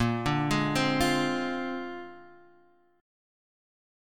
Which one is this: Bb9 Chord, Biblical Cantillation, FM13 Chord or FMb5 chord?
Bb9 Chord